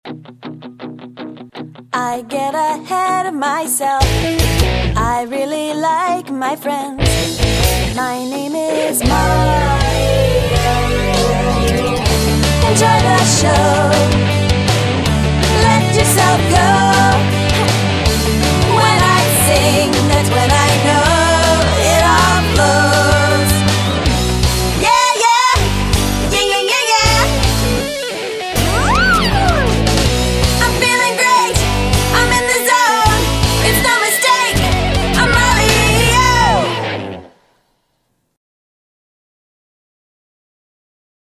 VOCAL DEMO
(0:39) animation demo track [648K]